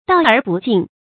道而不径 dào ér bù jìng
道而不径发音